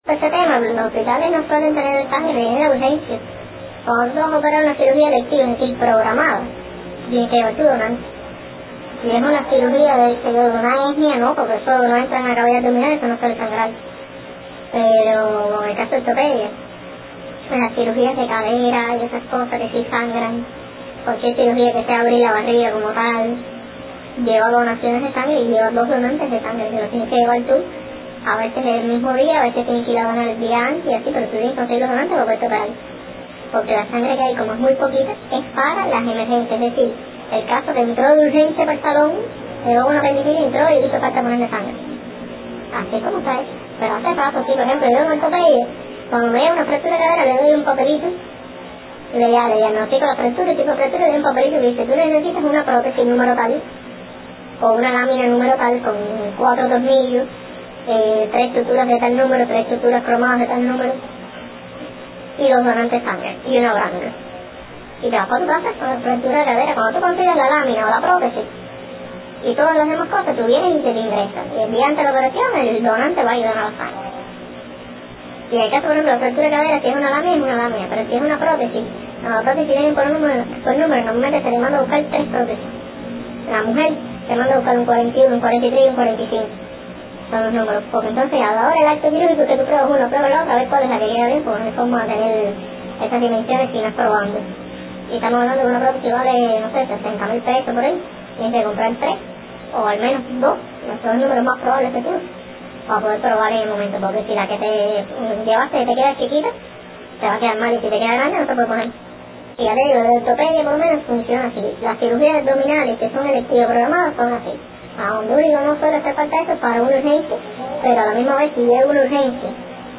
Declaraciones-donantes-de-sangre-medicos.ogg